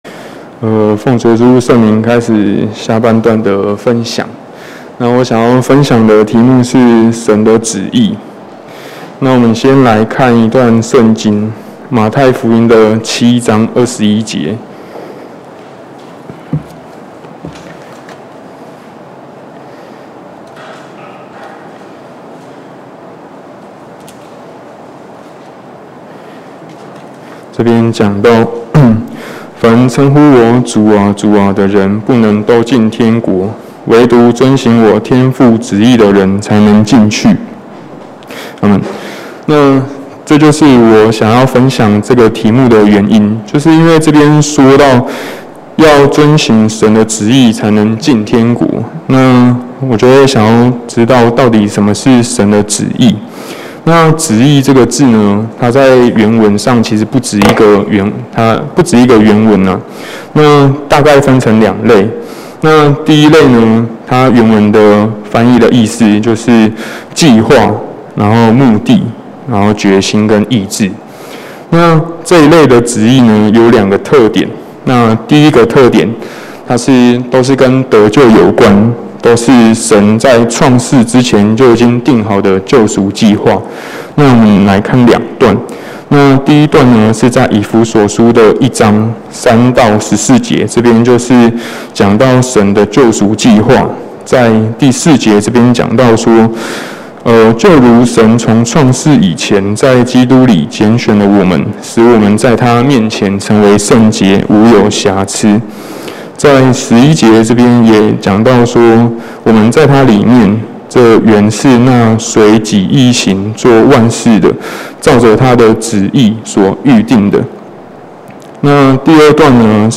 2021年10月講道錄音